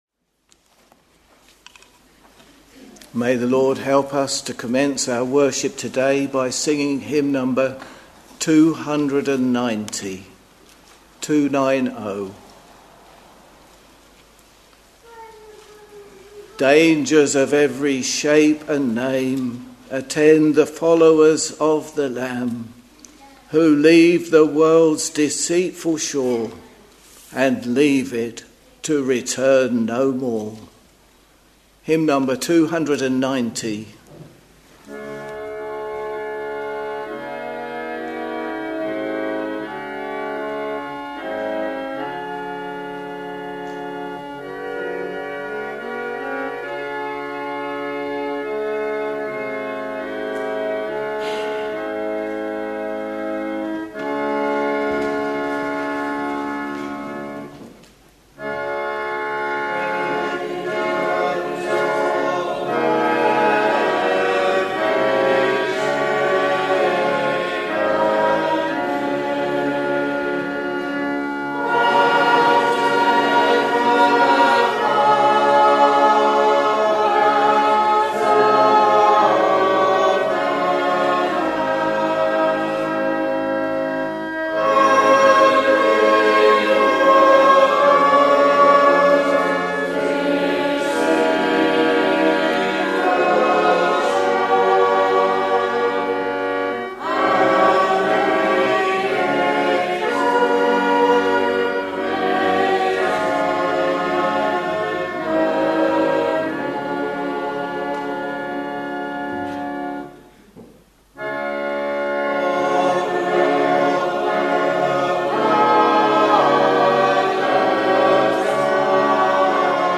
Sunday, 25th August 2024 — Morning Service